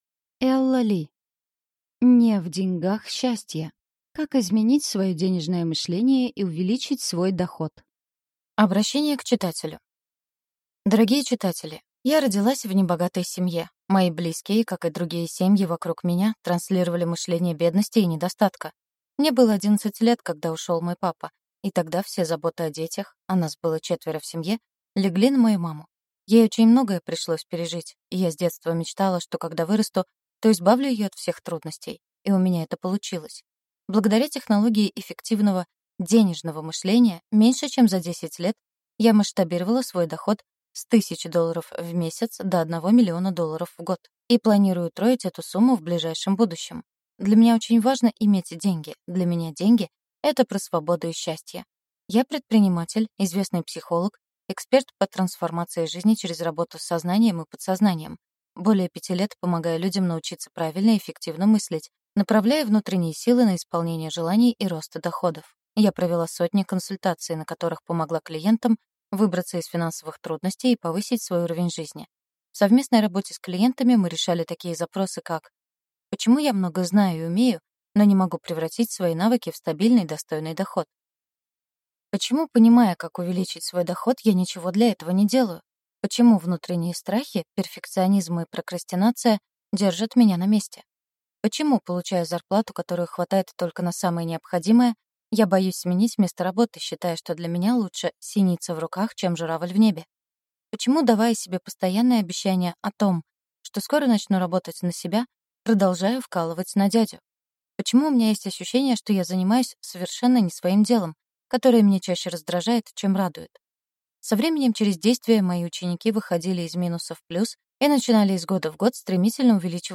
Аудиокнига (Не) В деньгах счастье. Как изменить свое денежное мышление и увеличить свой доход.